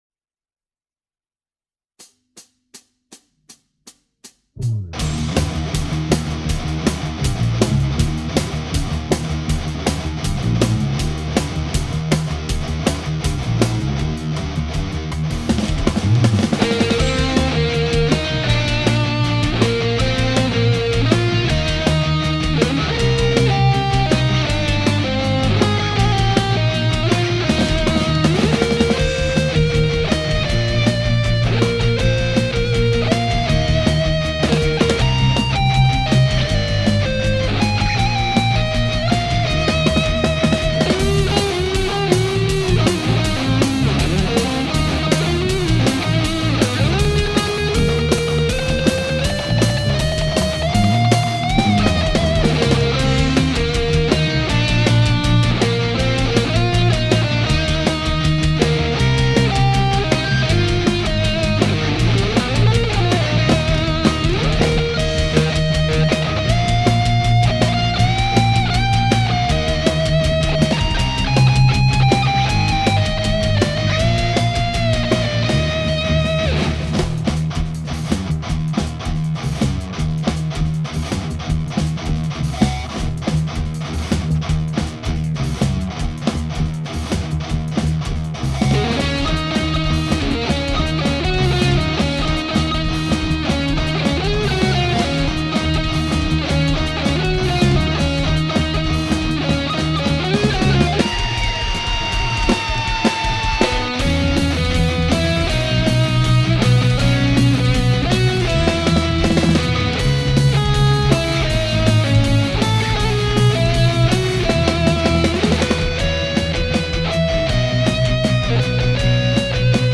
Happy_Birthday_Rock_Guitar_Version_320__kbps.mp3